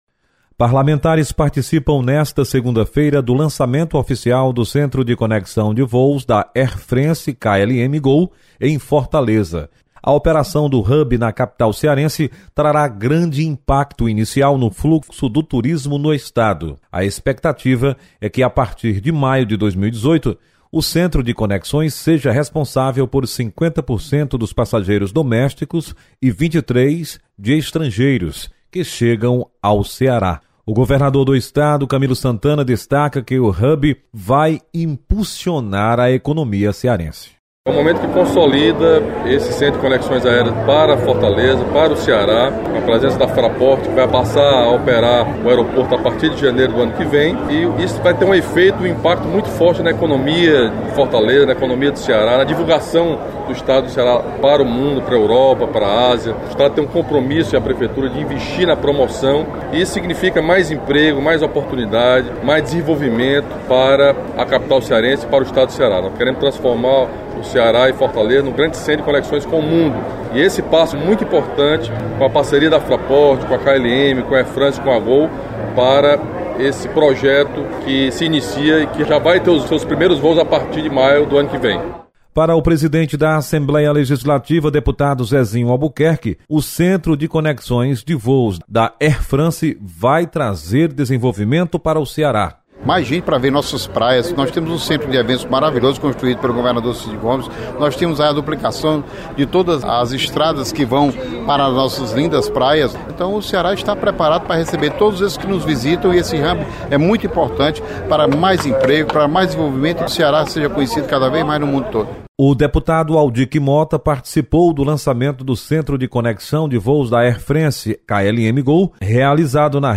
Presidente da Assembleia, deputado Zezinho Albuquerque, participa do lançamento do Hub da Airfrance em Fortaleza. Repórter